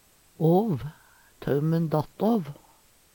åv - Numedalsmål (en-US)